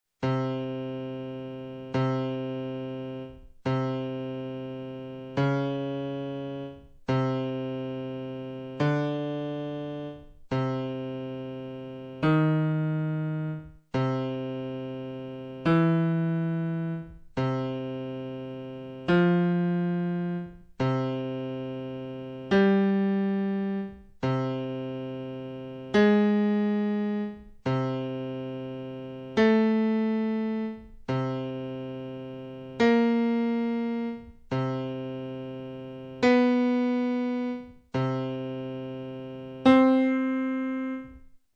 Misuriamo l'esatta distanza tonale degli intervalli maggiori, minori e giusti costruiti nella scala di Do maggiore: